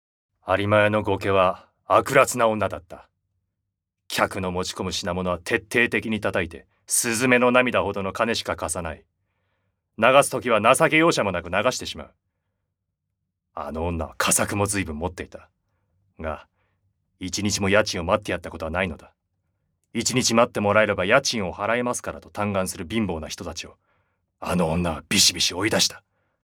セリフA
ボイスサンプル